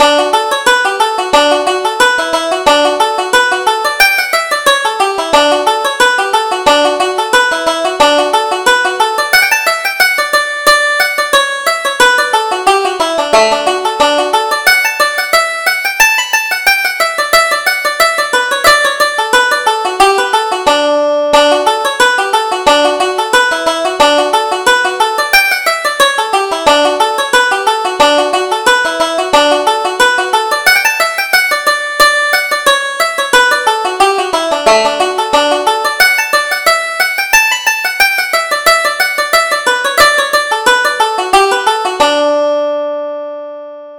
Reel: Early Rising